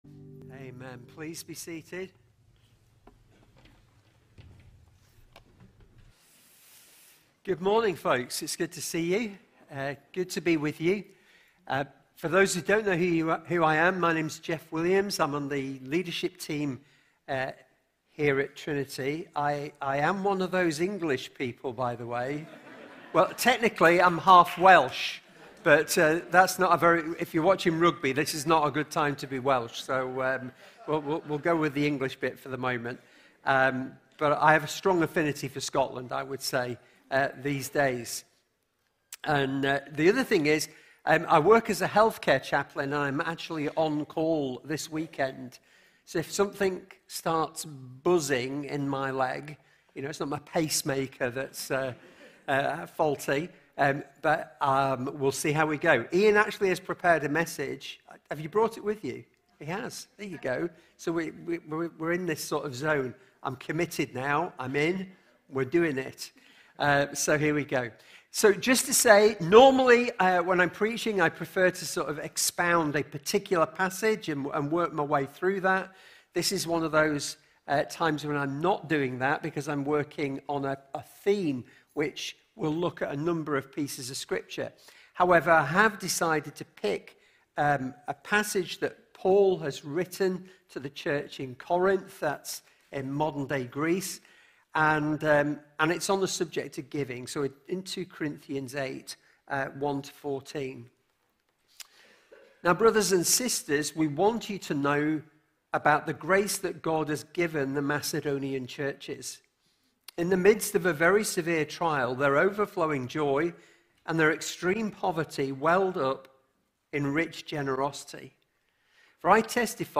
Sermons | Trinity Church of the Nazarene
Guest Speaker